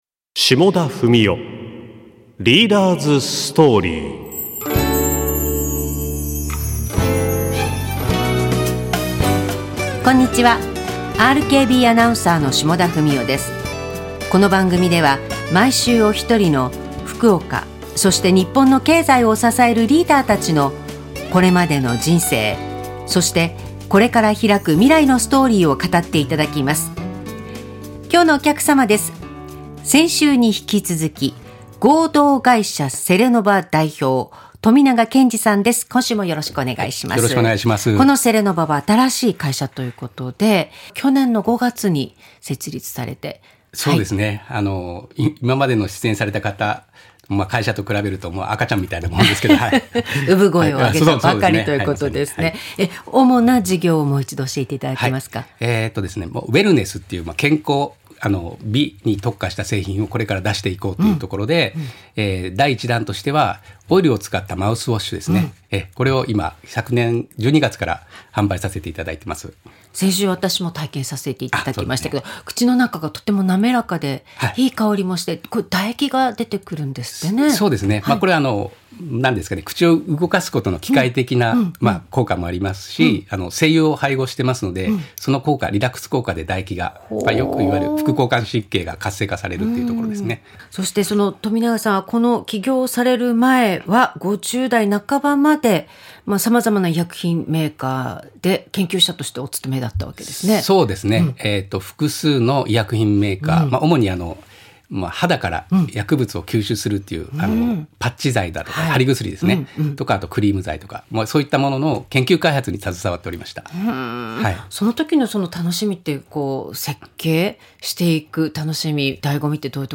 ラジオ